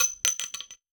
weapon_ammo_drop_23.wav